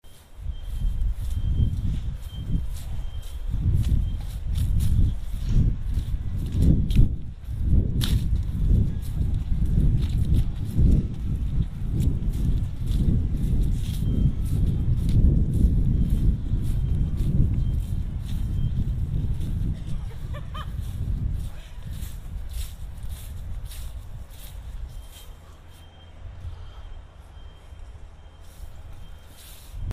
crunching leaves, wind, construction machinery beeps, a dancer laughs